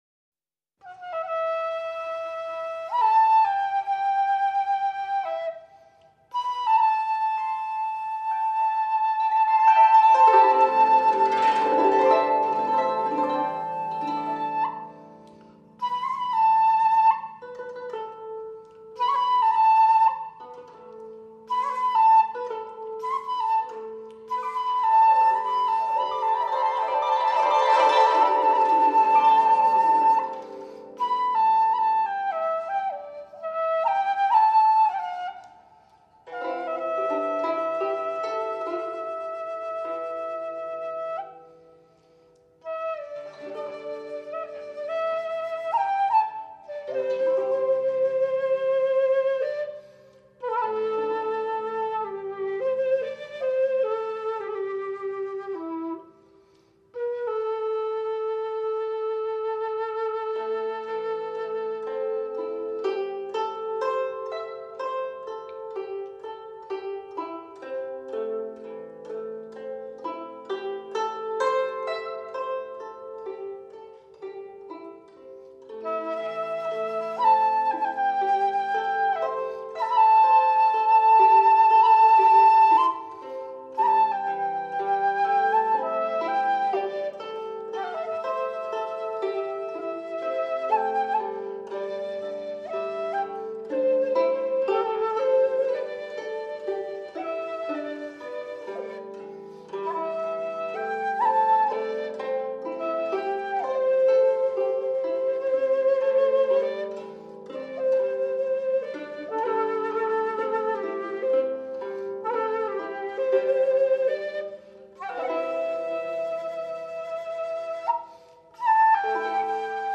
“埙之为器，立秋之音也”，音色幽深、悲凄、哀婉、绵绵不绝，古朴、典雅、神秘，最古老的乐音。
洞簫古箏二重奏